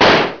ctf_ranged_rifle.ogg